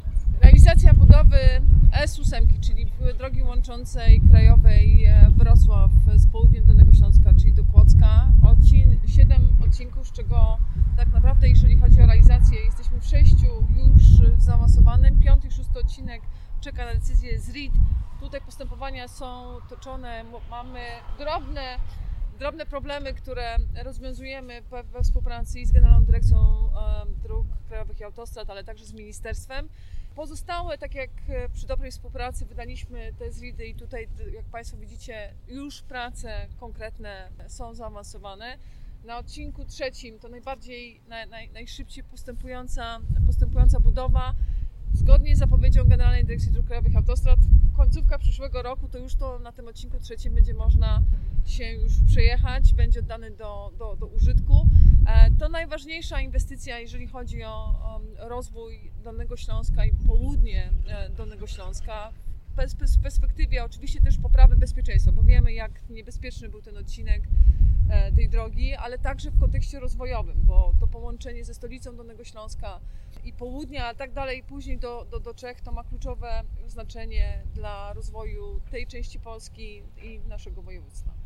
Całą inwestycję budowy drogi S8 pomiędzy Wrocławiem a Kłodzkiem podsumowuje Anna Żabska, wojewoda dolnośląska.